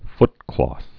(ftklôth, -klŏth)